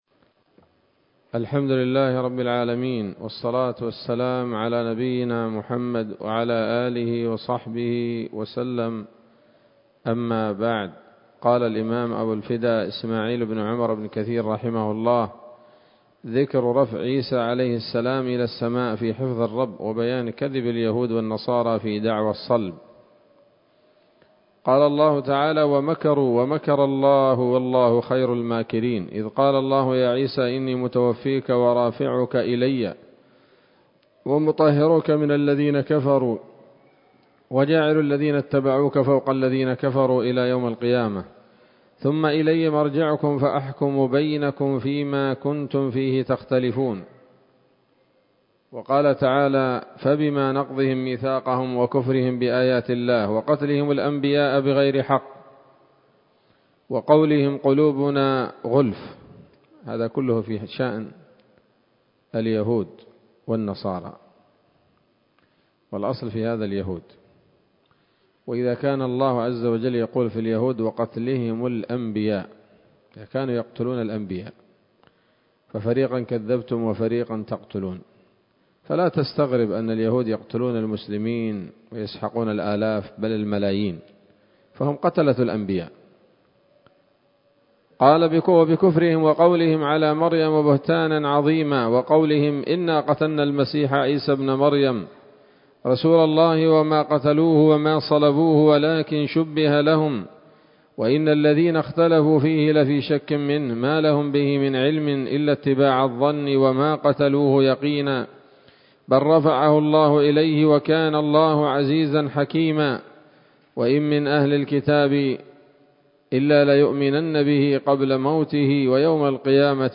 ‌‌الدرس الحادي والخمسون بعد المائة من قصص الأنبياء لابن كثير رحمه الله تعالى